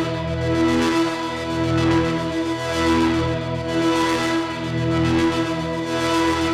Index of /musicradar/dystopian-drone-samples/Tempo Loops/110bpm
DD_TempoDroneB_110-E.wav